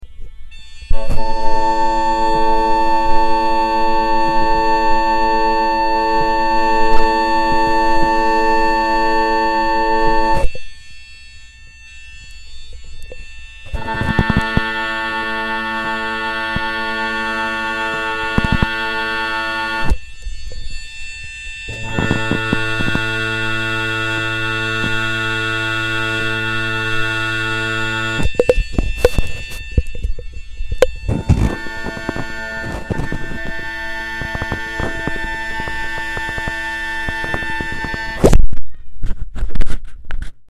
Below is a recording of a piezo (contact microphone) in contact with your bagpipe bag. It has 4 parts, depending on where I put the microphone. I am playing at low A = 440 Hz (MacLellan A440 chanter) and my drones are just slightly out of tune (a fact that I regret, sort of – it gives you an idea of how much one can hear from the piezo’s perspective if they are a little out of tune). Anyways, the first part is the piezo in between the blowpipe stock and chanter stock, right on top (bagchanter). The second part, the piezo is moved to in between the blowpipe stock and the drone stocks (bagblowpipe). The third part, the piezo is placed underneath the bag, behind the chanter (bagbottom).
The chanter is playing high A the whole time.